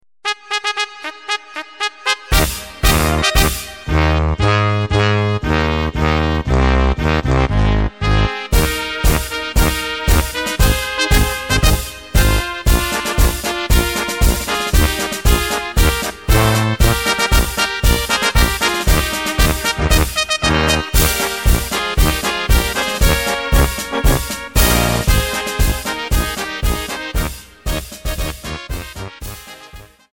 Takt: 2/4 Tempo: 116.00 Tonart: Ab Lyrics
Vereinshymne